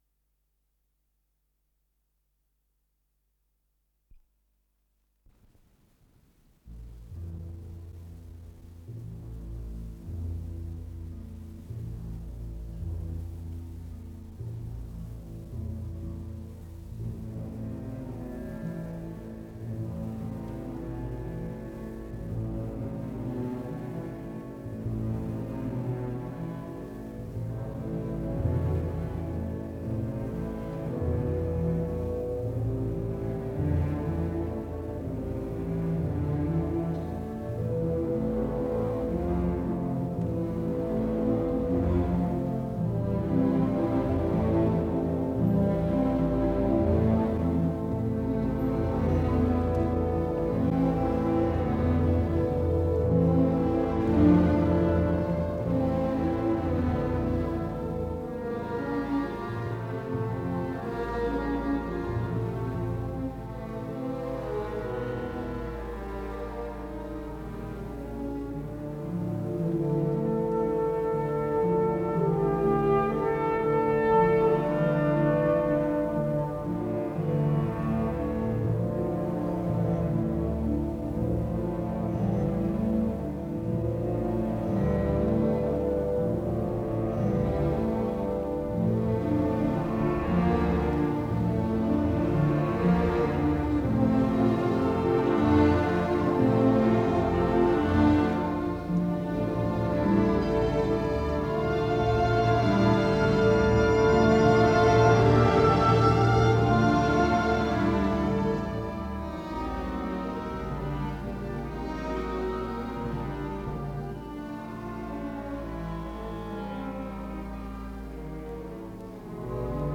Исполнитель: Государственный симфонический оркестр СССР
Симфоническая поэма
ля минор